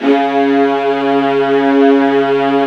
55u-va01-C#2.wav